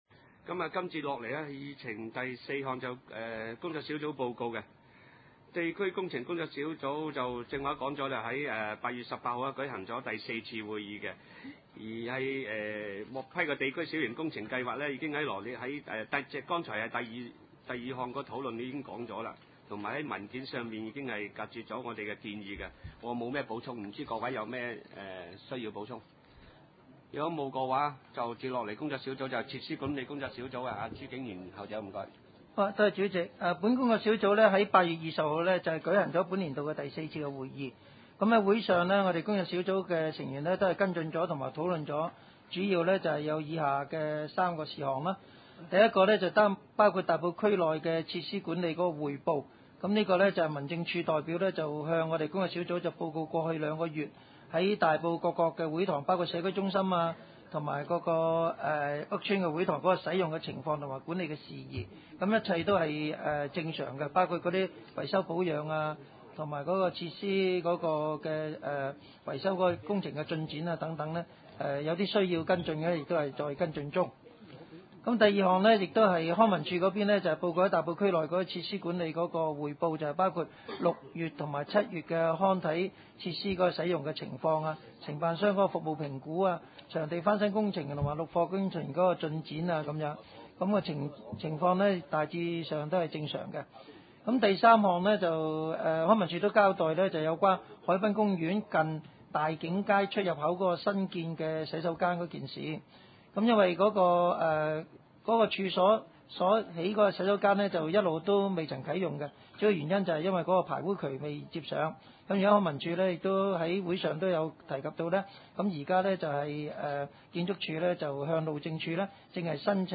地區設施管理委員會2010年第五次會議
地點：大埔區議會秘書處會議室